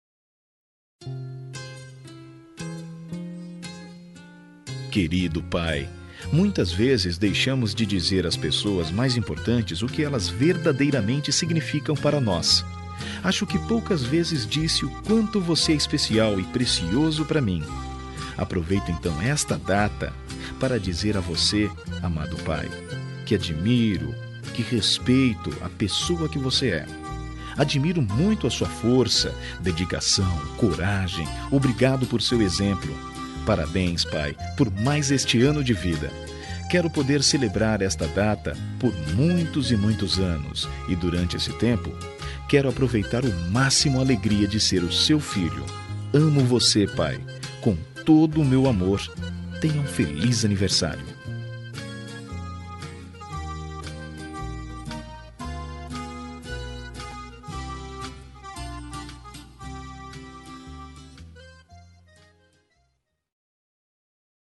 Aniversário de Pai – Voz Masculina – Cód: 11598